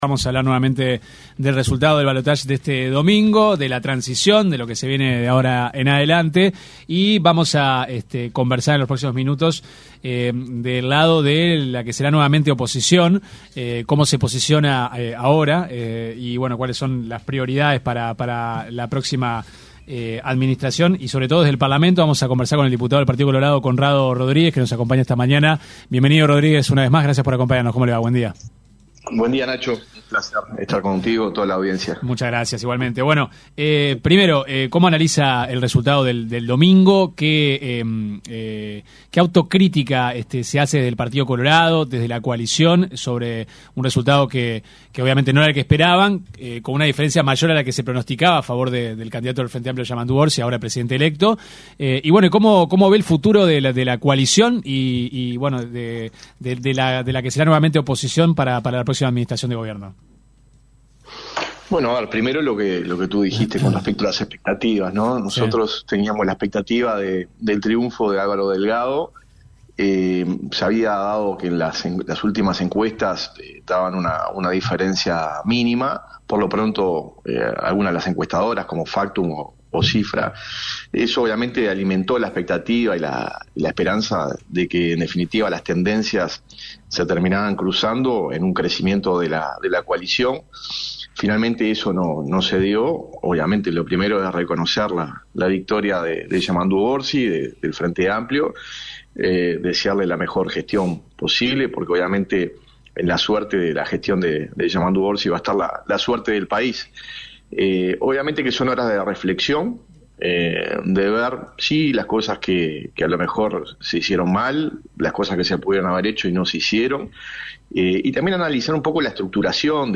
Escuche la entrevista completa aquí: El diputado reelecto por el Partido Colorado, Conrado Rodríguez, en diálogo con 970 Noticias, realizó una autocrítica tras el resultado del balotaje.